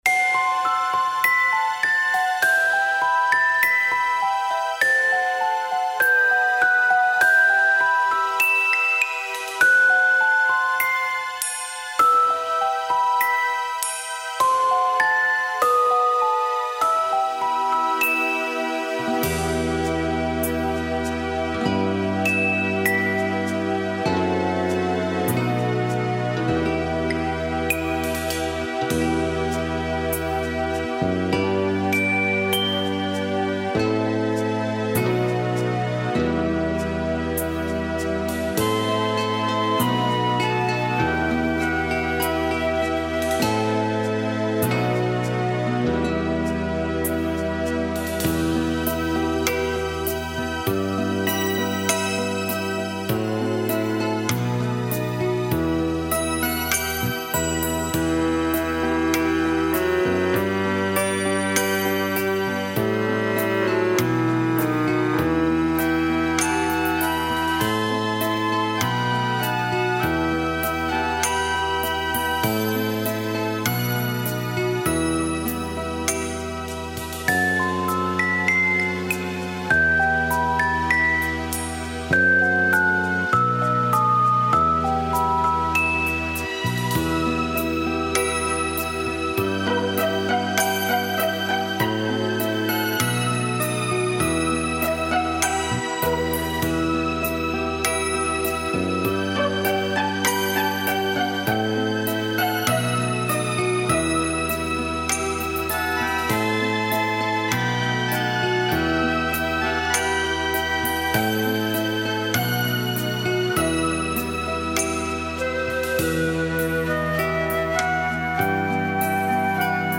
Минусовки: